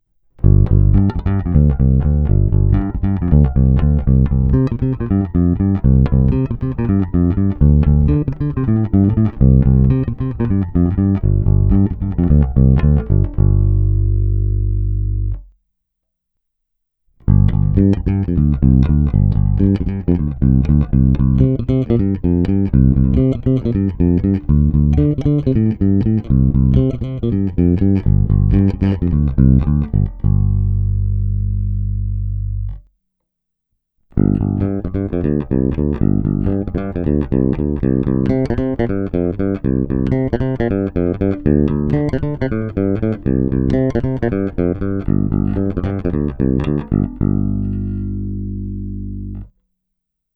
Není-li uvedeno jinak, následující nahrávky jsou provedeny rovnou do zvukové karty, v pasívním režimu a s plně otevřenou tónovou clonou.